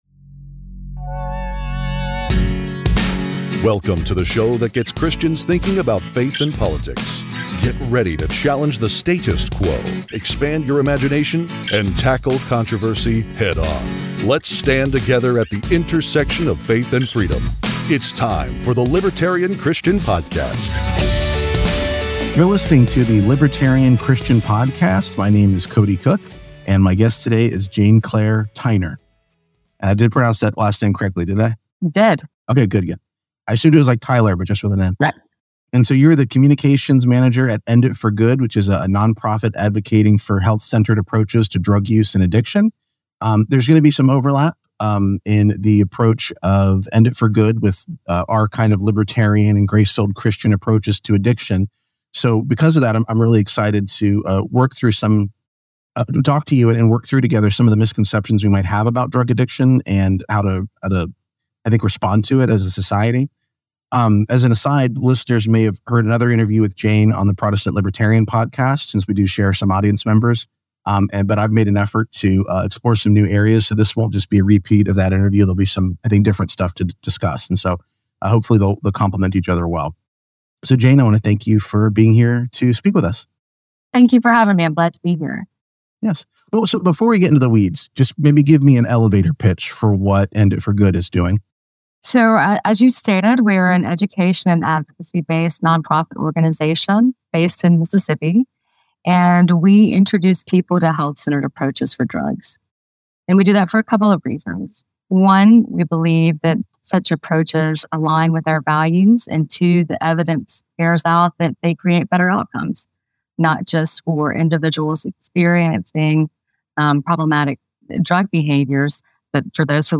This compelling conversation challenges conventional views on drug addiction and explores health-centered approaches over punitive measures.